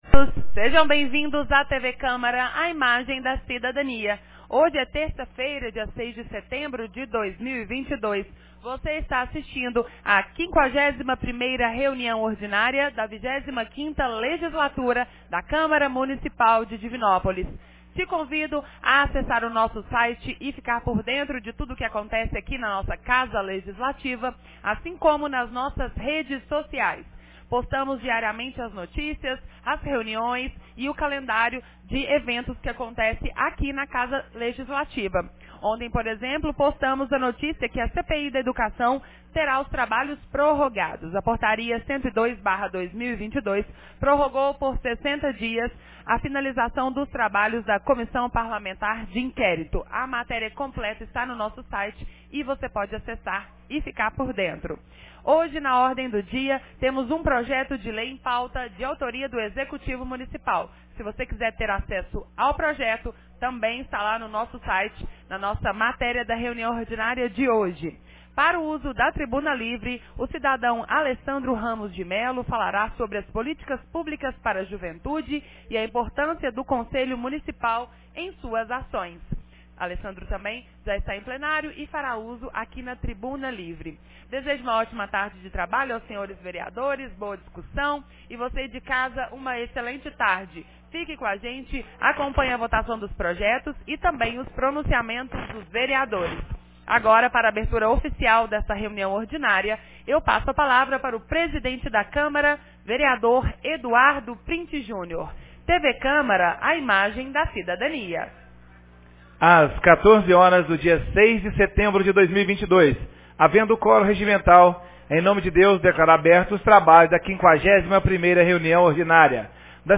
51ª Reunião Ordinária 06 de setembro de 2022